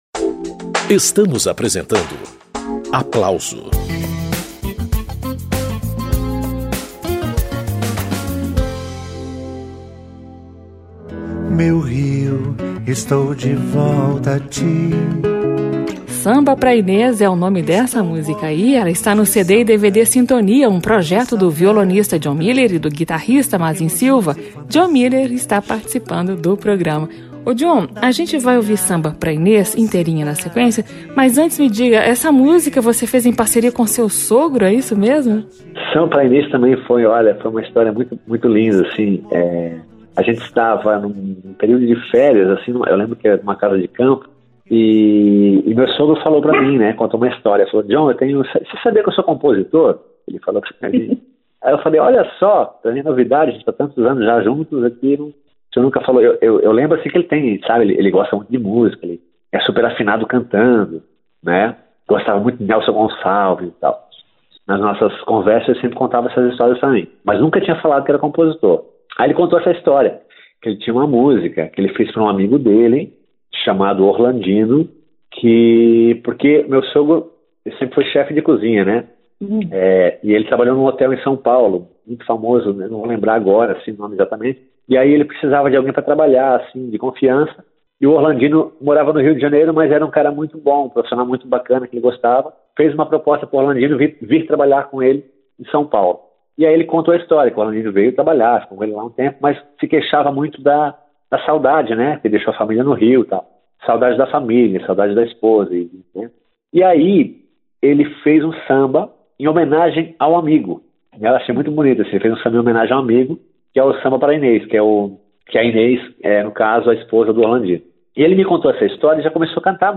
Diálogo entre violão e guitarra
baladas, sambas e bossas, em formato minimalista